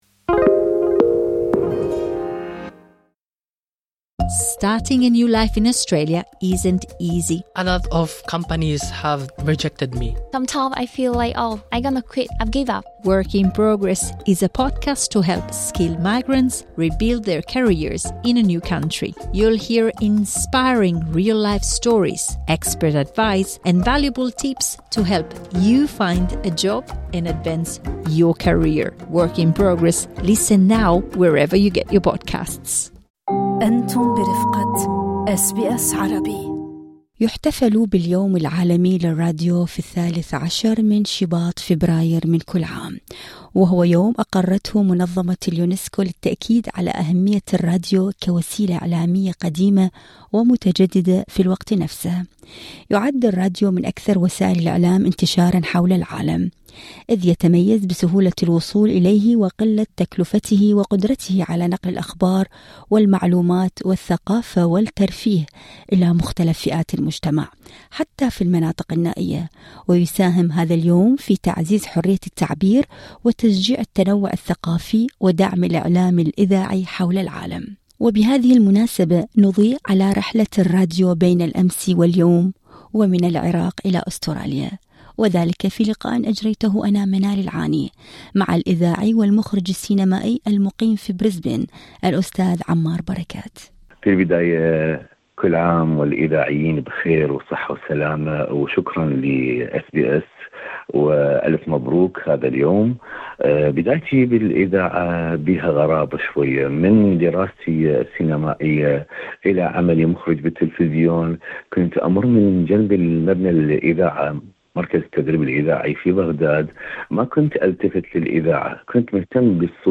وبهذه المناسبة، نضيء على رحلة الراديو بين الأمس واليوم ومن العراق إلى أستراليا وذلك في اللقاء الصوتي اعلاه